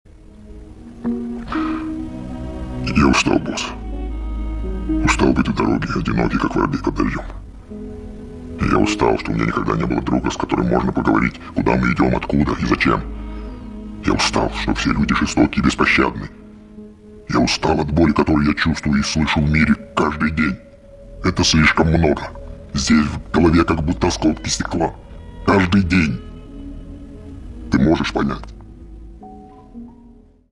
• Качество: 320, Stereo
грустные
из фильмов
печальные
низкий мужской голос
цикличные